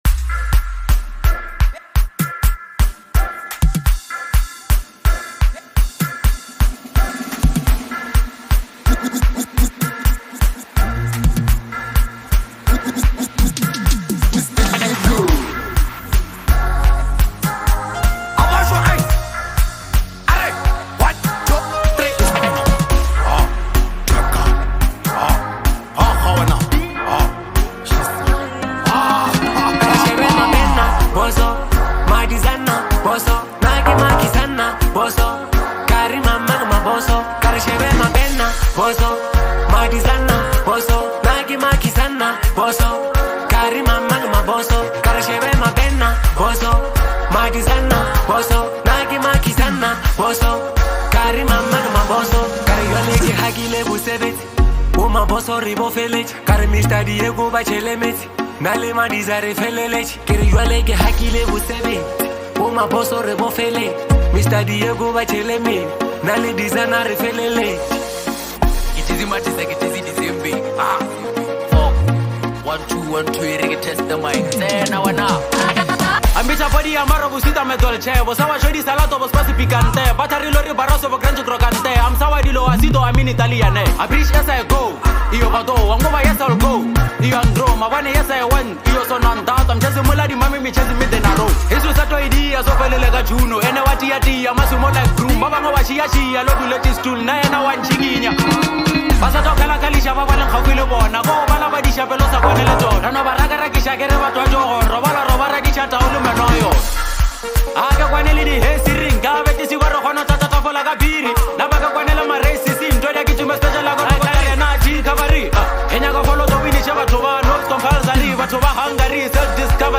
infectious groove and memorable melodies
Lekompo